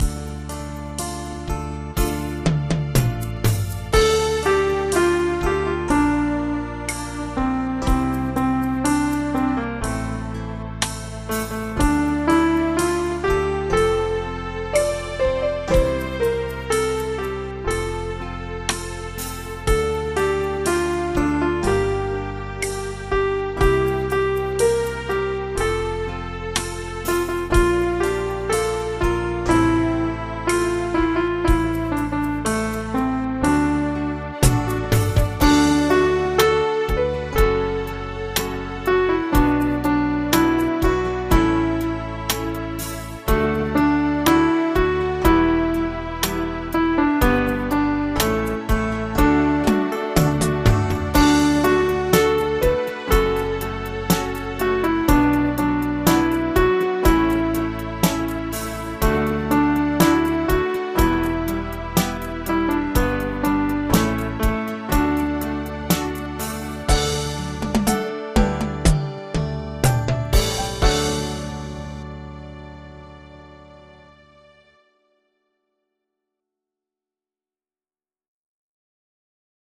Audio Midi Bè 01: download